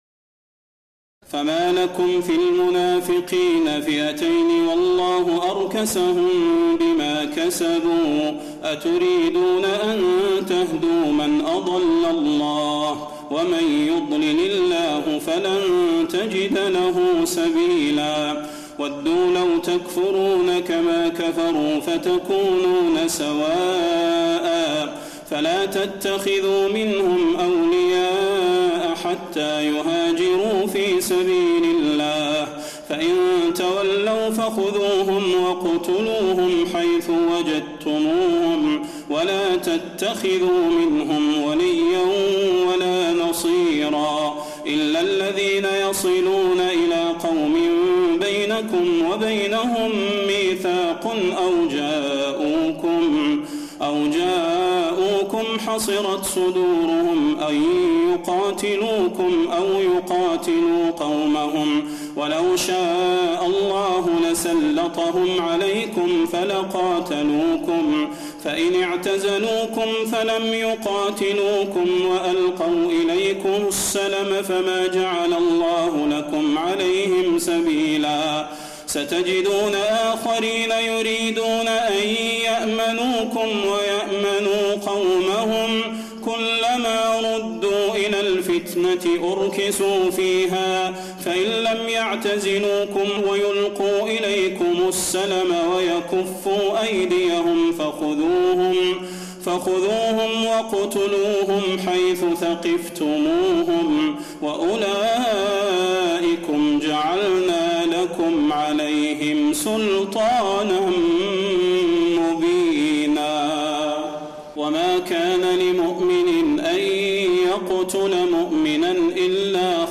تراويح الليلة الخامسة رمضان 1432هـ من سورة النساء (88-147) Taraweeh 5 st night Ramadan 1432H from Surah An-Nisaa > تراويح الحرم النبوي عام 1432 🕌 > التراويح - تلاوات الحرمين